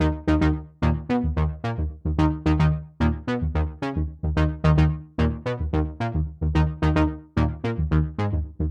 描述：低音/合成器循环，速度110 bpm，四小节
Tag: 110 bpm Rap Loops Synth Loops 1.47 MB wav Key : D